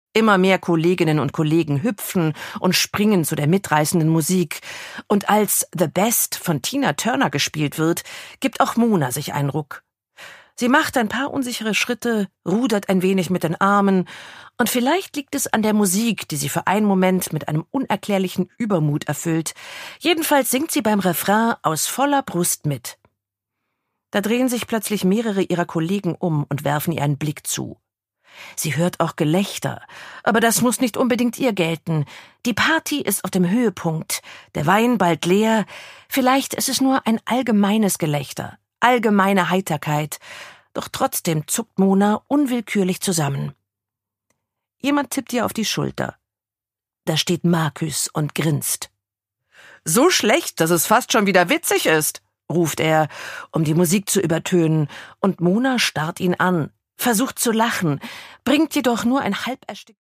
Line Baugstø: Evil Grandma (Ungekürzte Lesung)
Produkttyp: Hörbuch-Download